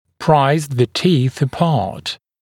[praɪz ðə tiːθ ə’pɑːt][прайз зэ ти:с э’па:т]раздвигать зубы, действуя как рычаг